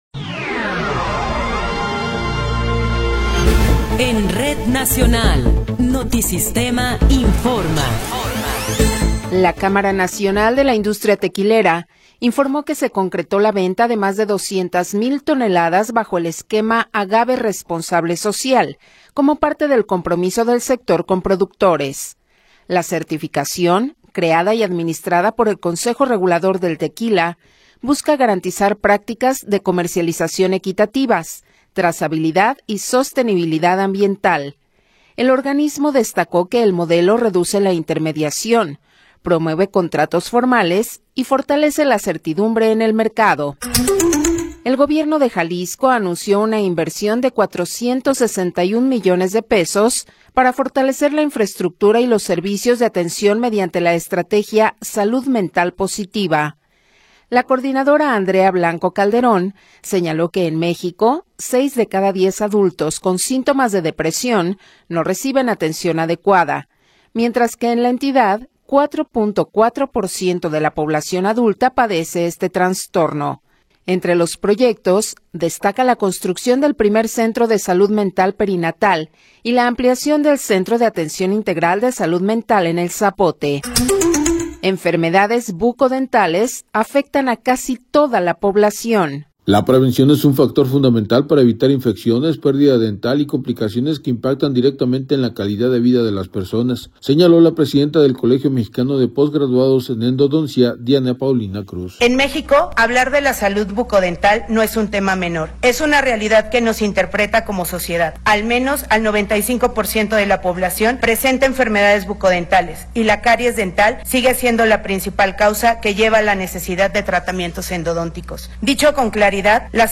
Noticiero 15 hrs. – 7 de Marzo de 2026
Resumen informativo Notisistema, la mejor y más completa información cada hora en la hora.